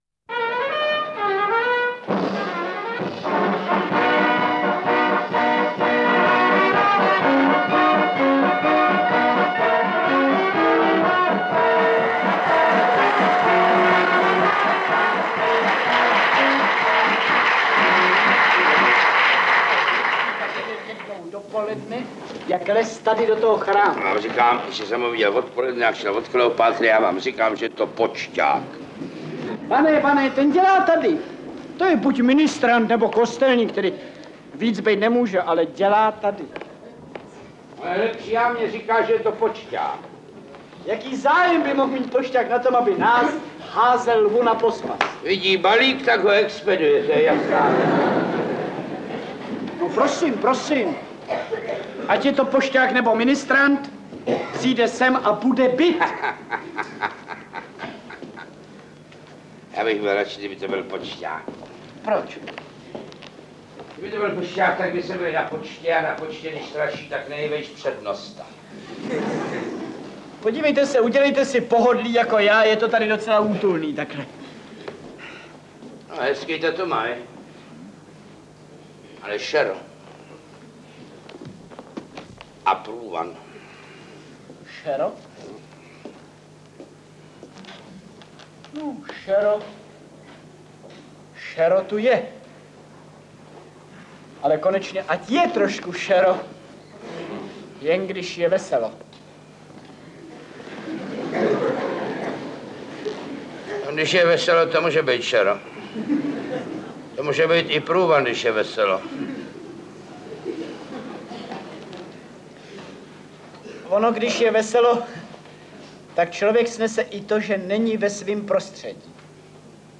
Interpreti:  Miroslav Horníček, Jan Werich